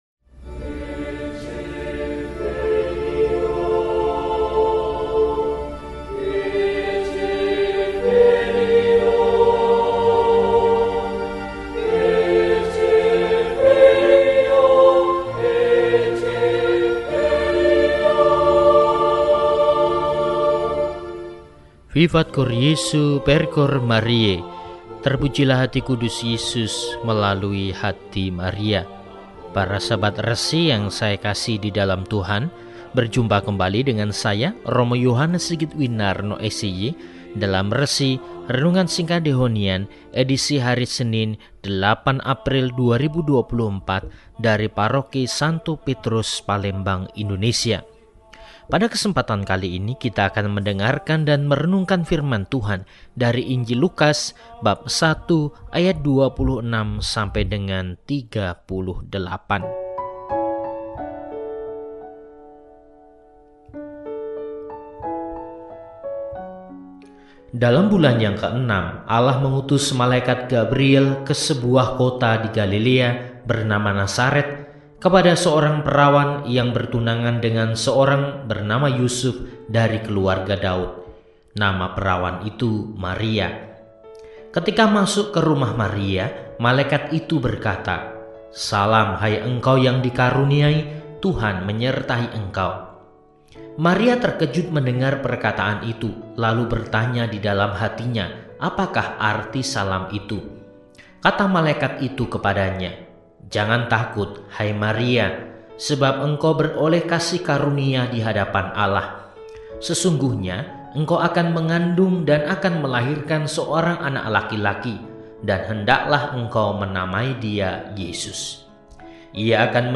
Senin, 08 Maret 2024 – HARI RAYA KHABAR SUKACITA – RESI (Renungan Singkat) DEHONIAN